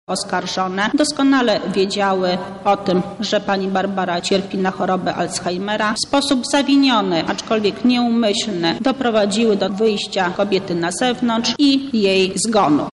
– Oskarżone nie zamknęły drzwi wyjściowych, a także nie były obecne przy monitoringu – mówi sędzia Beata Górna-Gielara